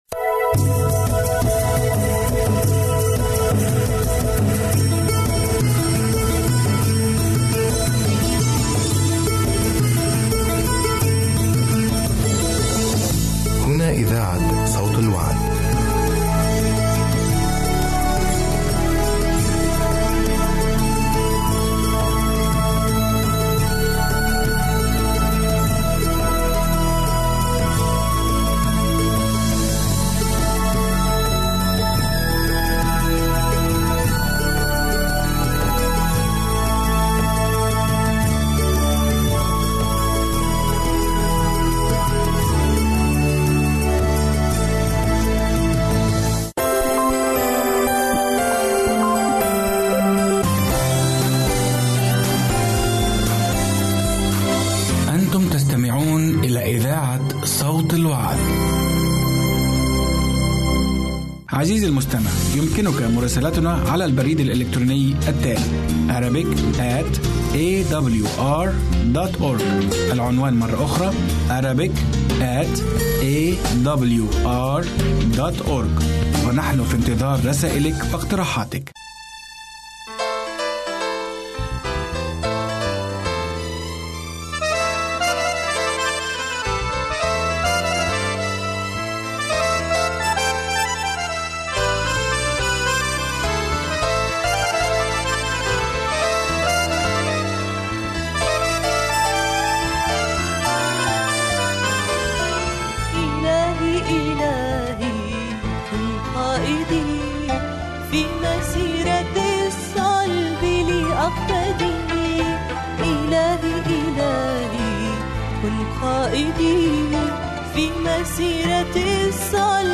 برنامج إذاعي يومي باللغة العربية AWR يتضمن برامج مقابلة ، حياة عائلية ، جولة مع أمثال المسيح.